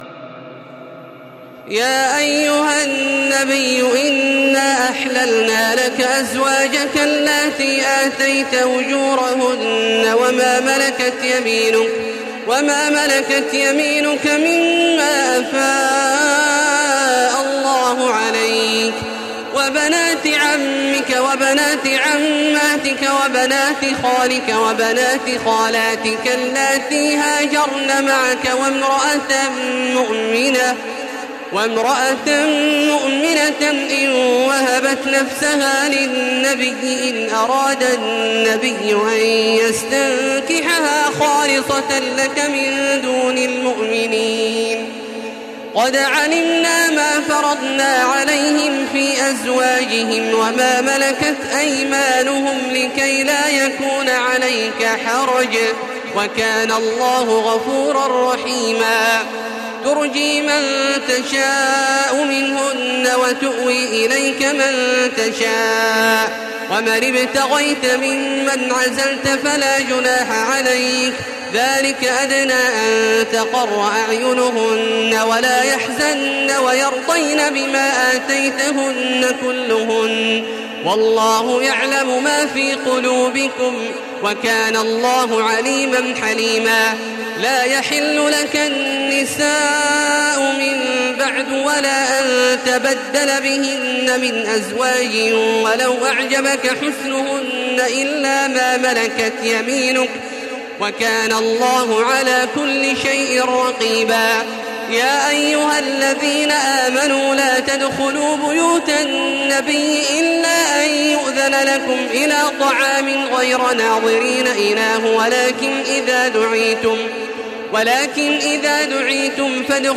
تراويح ليلة 22 رمضان 1435هـ من سور الأحزاب (50-73) وسبأ و فاطر (1-14) Taraweeh 22 st night Ramadan 1435H from Surah Al-Ahzaab to Faatir > تراويح الحرم المكي عام 1435 🕋 > التراويح - تلاوات الحرمين